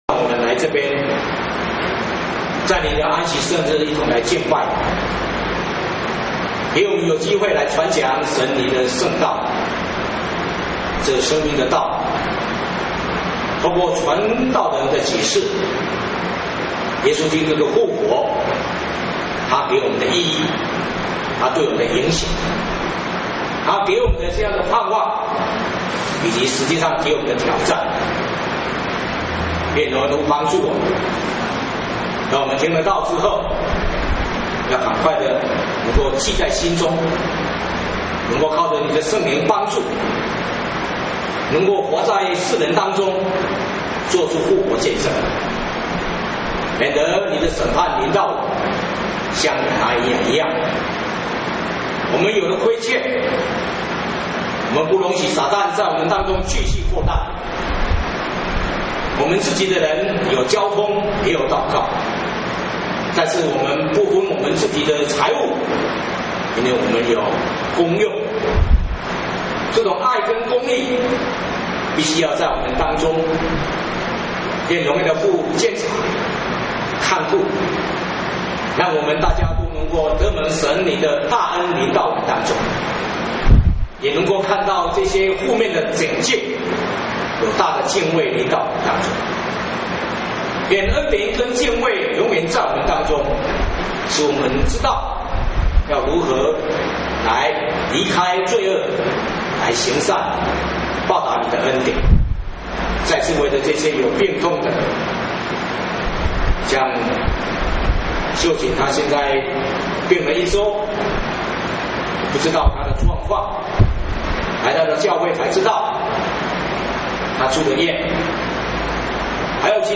（徒 4:32-5:11）-1 講解